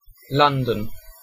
Ääntäminen
Ääntäminen : IPA : /ˈlʌn.dən/ UK : IPA : [ˈlʌn.dən] Haettu sana löytyi näillä lähdekielillä: englanti Käännös Erisnimet 1.